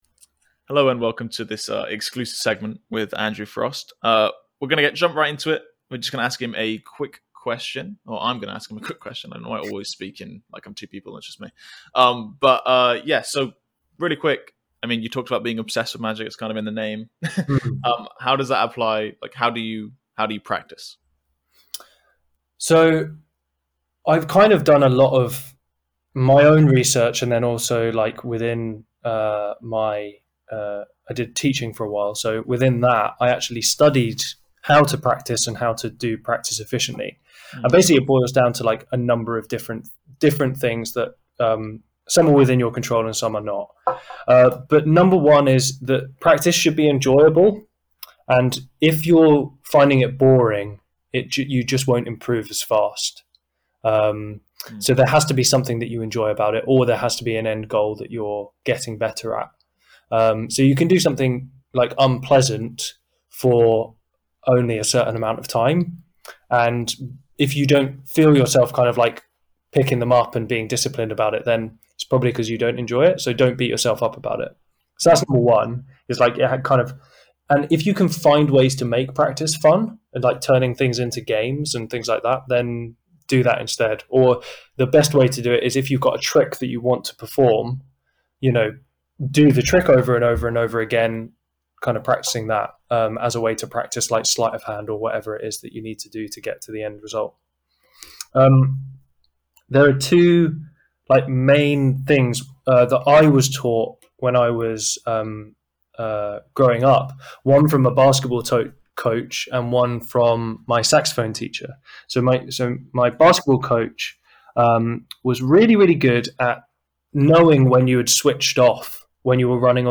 During a conversation we recorded exclusively for buyers, he gave me a magic practice tip I’ll NEVER forget.